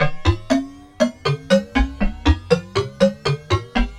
Spike Lead.wav